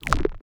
Clicky Crunch.wav